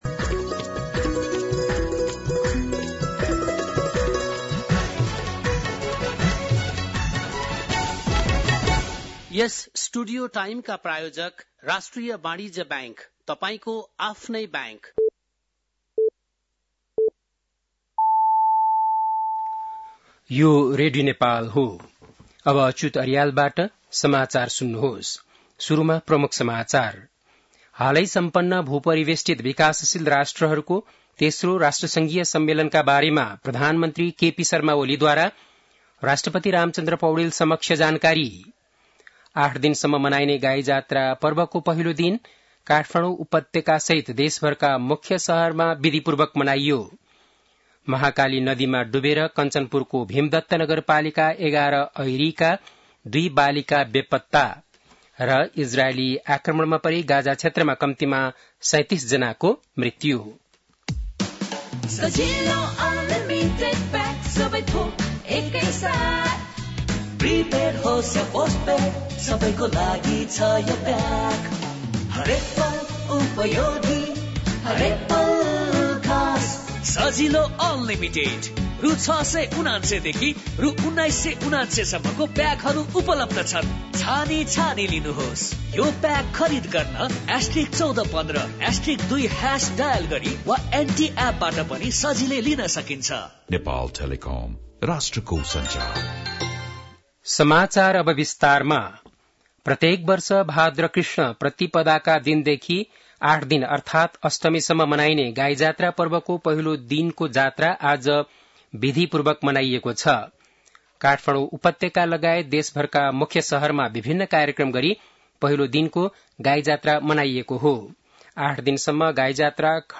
बेलुकी ७ बजेको नेपाली समाचार : २५ साउन , २०८२
7-pm-nepali-news-4-25.mp3